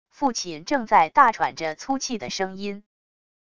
父亲正在大喘着粗气的声音wav音频